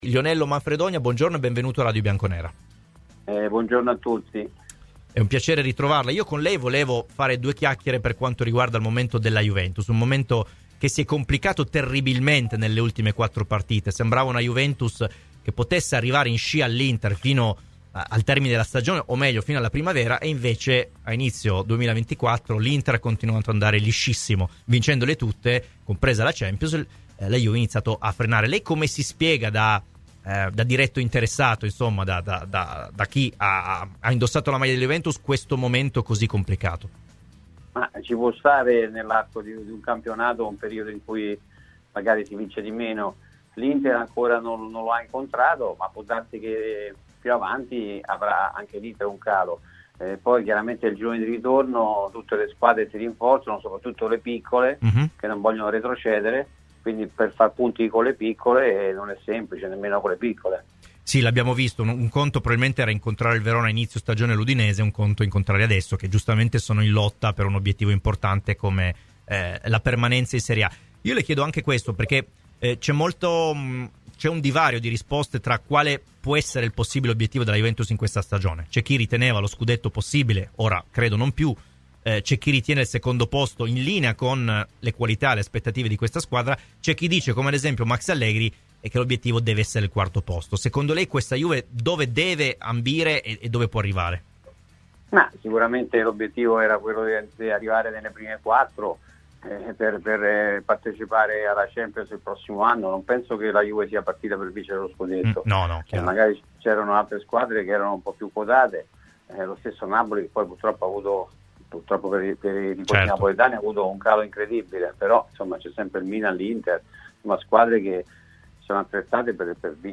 L'ex difensore ospite a "L'Ora dellaVecchia Signora": "Bremer in Inghilterra? Di fronte ad una grande offerta bisogna sempre vendere"